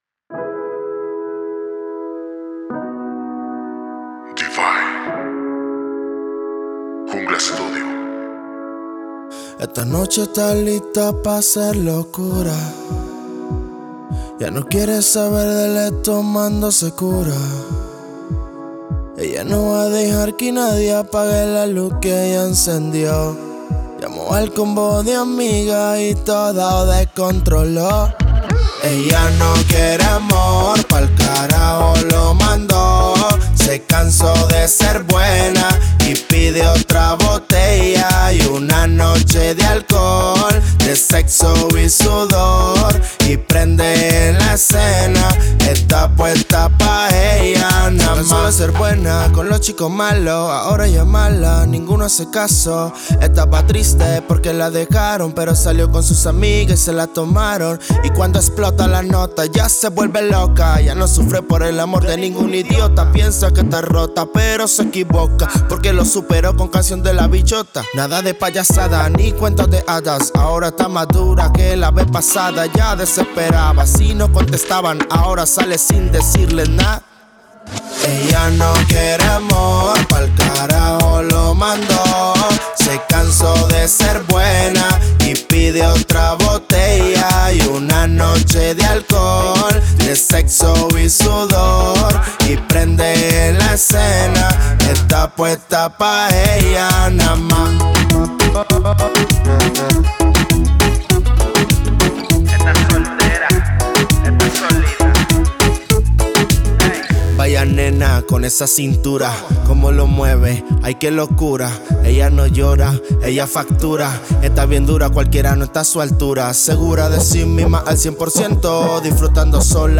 POP REGGAETON